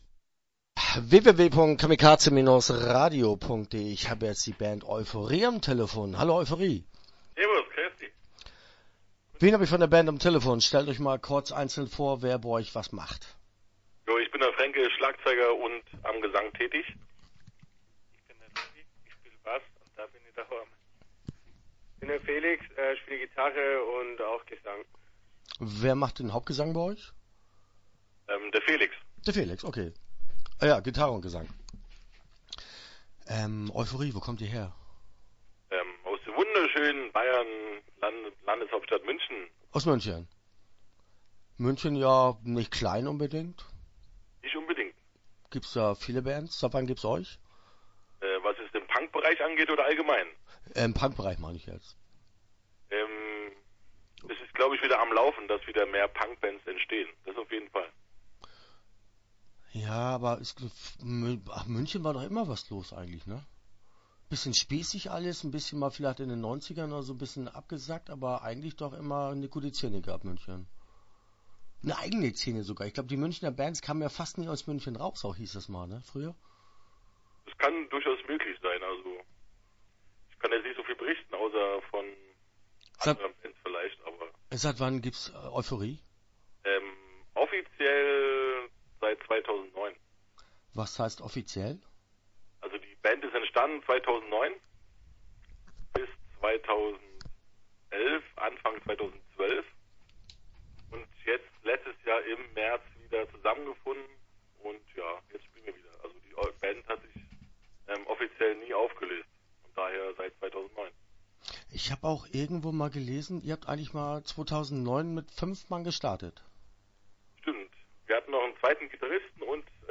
Start » Interviews » Euphorie